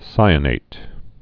(sīə-nāt, -nət)